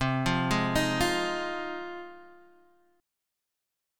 Ab6/C Chord (page 2)
Listen to Ab6/C strummed